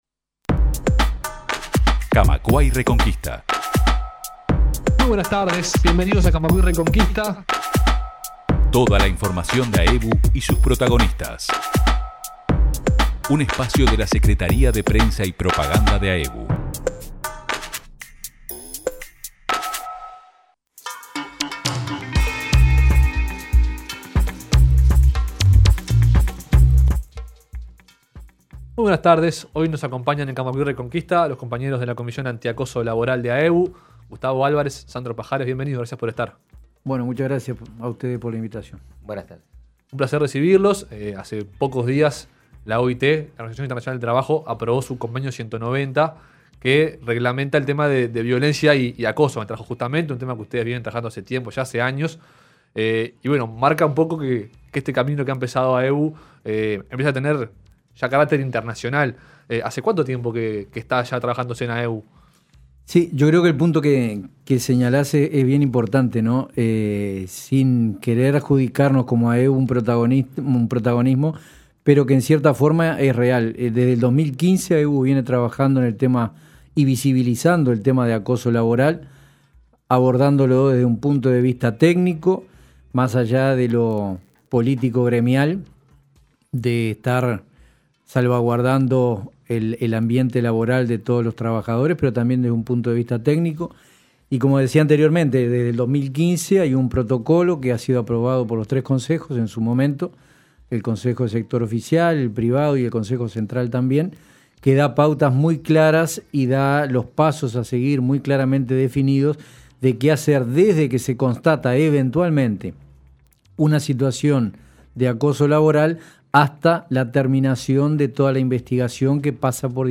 Una charla donde se exponen algunas respuestas a estas preguntas, pero también se plantean otras para seguir discutiendo.